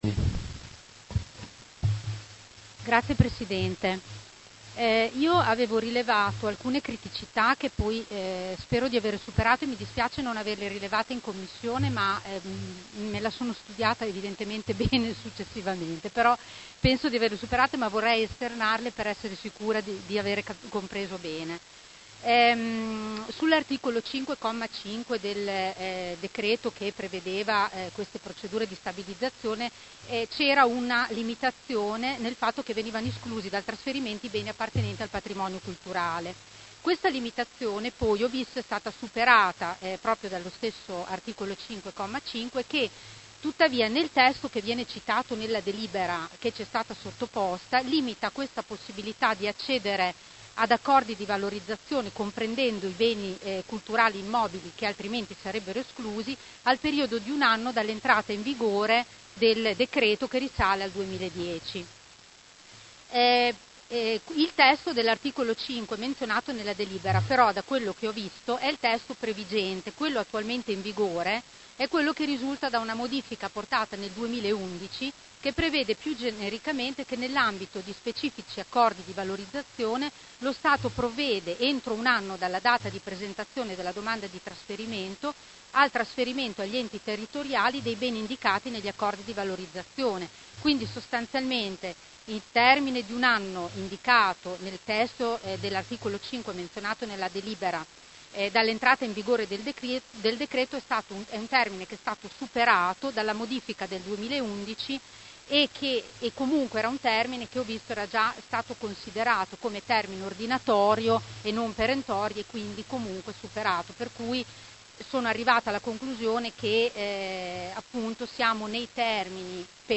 Seduta del 17/10/2019 Dibattito. Delibera Prot. Gen. 291060 Acquisizione beni culturali appartenenti al Demanio dello Stato in attuazione dell'art. 5, comma 5, del D.Lgs. 28 maggio 2010 n. 85 “Federalismo demaniale” - Accordi di valorizzazione “Immobili Palazzo Solmi”, “Alloggi via Bonacorsa” area ex Colombofili e “Chiesetta Ricci”